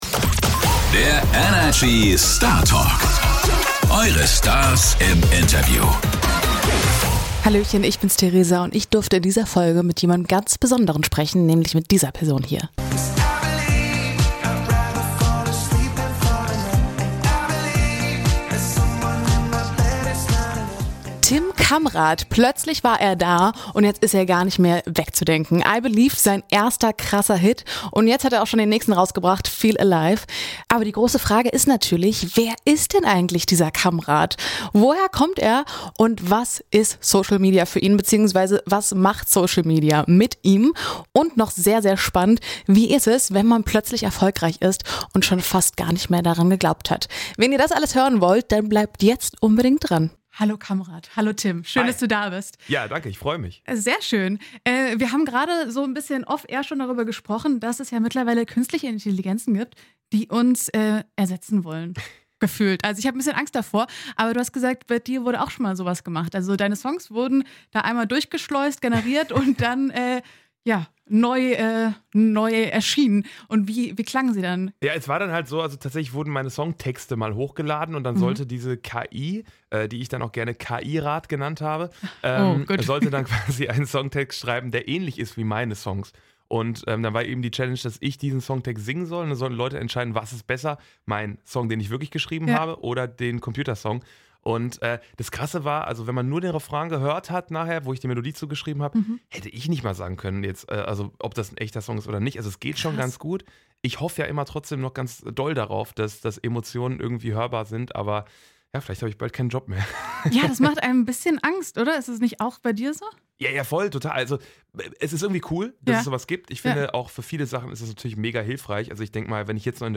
Einige Parallelen zwischen ihm und Hundewelpen kann man auf jeden Fall ziehen und auch sein poetisches Talent beweist er direkt im Interview. Außerdem hat er uns erzählt, weshalb der Moment, in dem sein Hit zum ersten Mal im Radio gespielt wurde, etwas in den Hintergrund rückte und was seine Eltern damit zu tun haben.